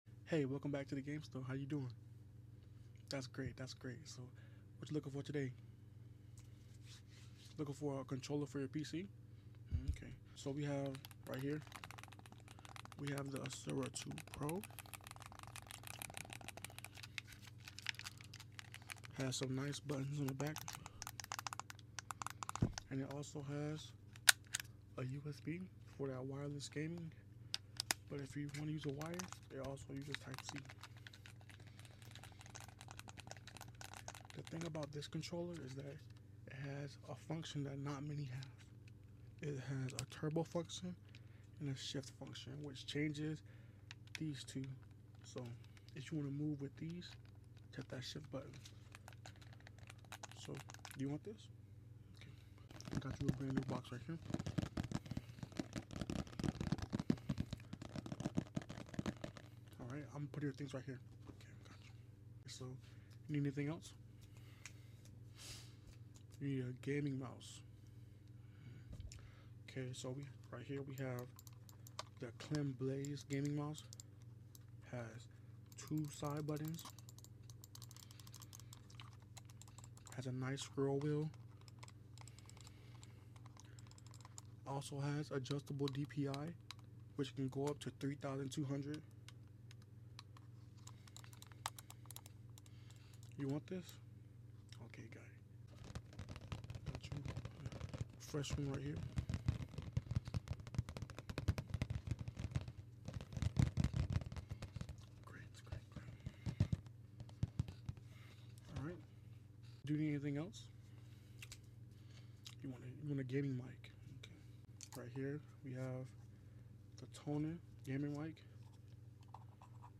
Gamestore ASMR And if you sound effects free download